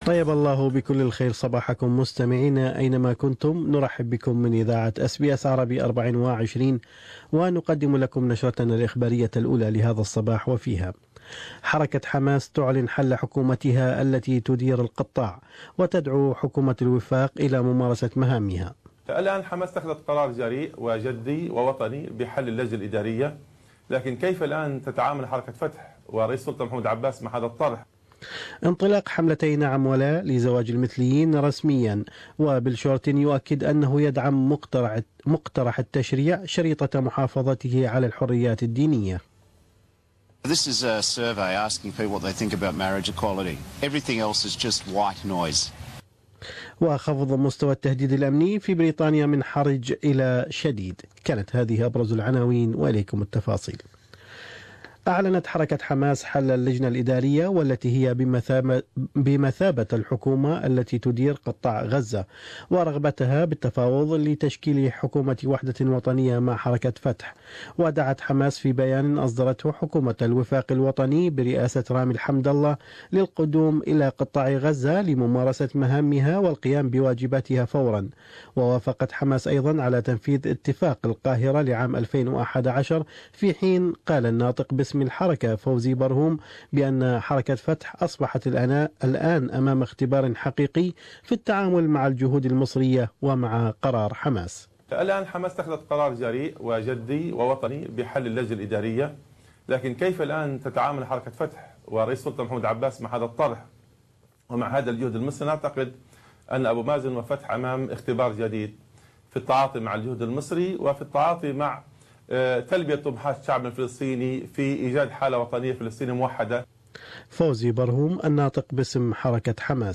News Bulletin: The U-K lowers its national theat level to severe